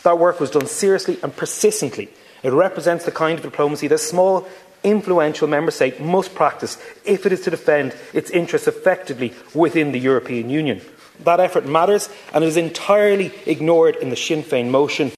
The Minister of State for Foreign Affairs told the debate  the opposition is conveniently ignoring all the hard work done by Irish officials to improve the Mercosur deal.